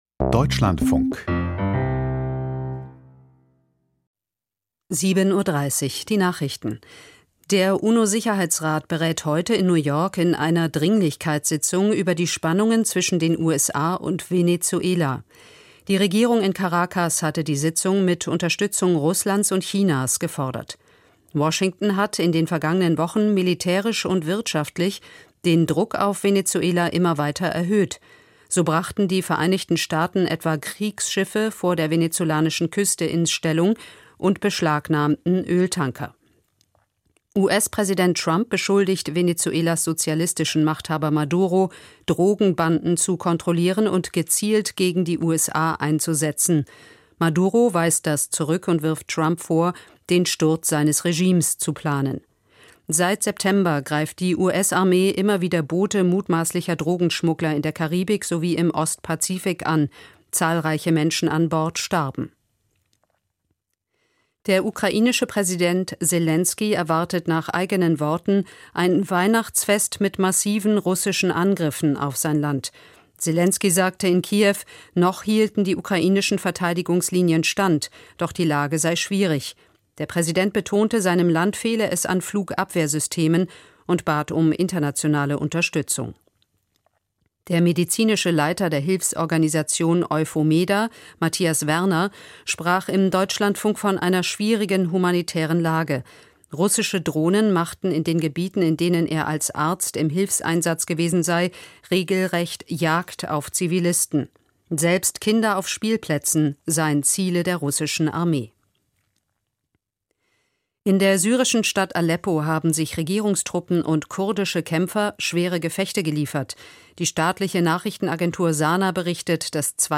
Die Nachrichten vom 23.12.2025, 07:30 Uhr